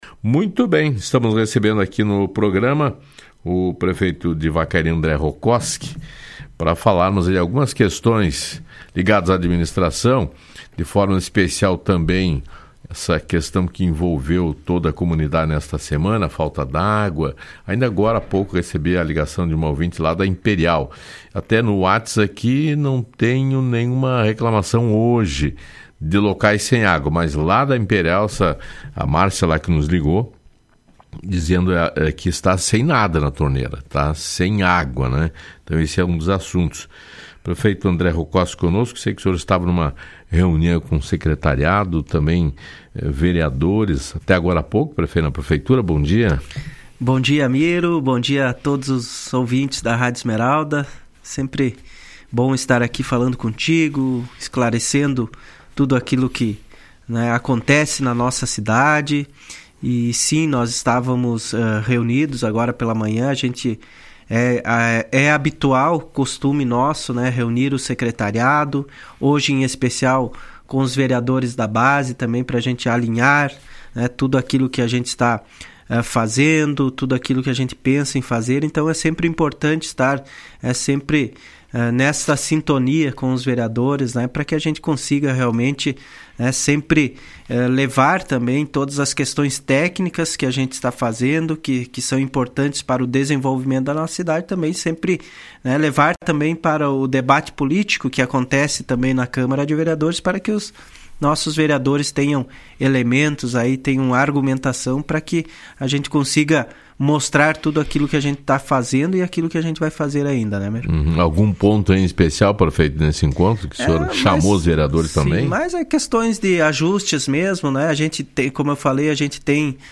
Na manhã desta sexta-feira, 27, o prefeito de Vacaria, André Rokoski esteve na Rádio Esmeralda e o principal assunto foi sobre os problemas de abastecimento de água que a comunidade vem enfrentando desde segunda-feira, dia 23.